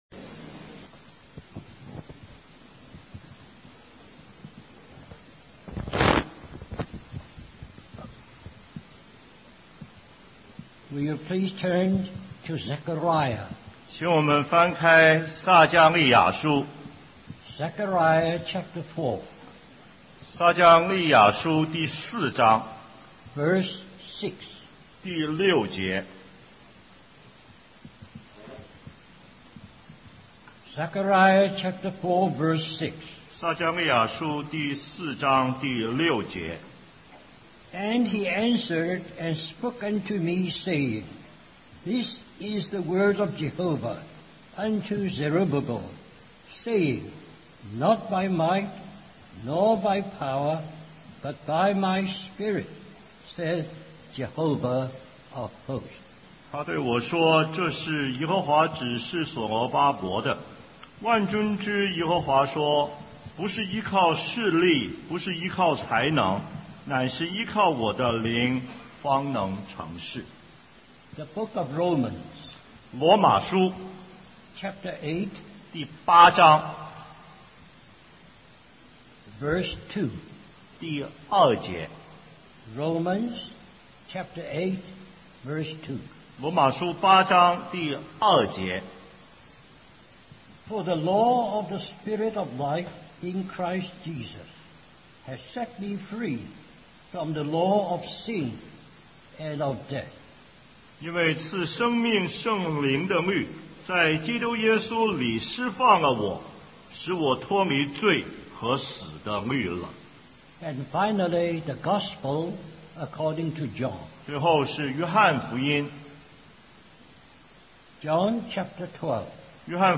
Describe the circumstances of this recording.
2009 Special Conference For Service, Singapore Stream or download mp3 Summary Our brother shares from the conference theme of "Christ Centered Service". Note: Poor recording quality from minute 41-44.